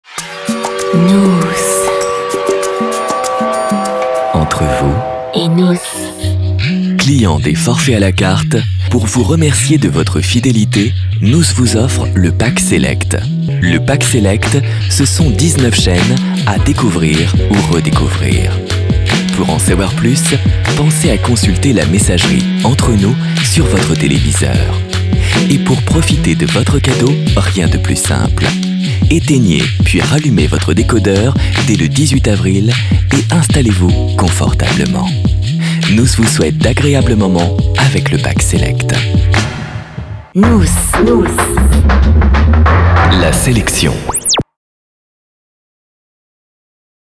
NOOS guide vocal, habillage - Comédien voix off
Genre : voix off.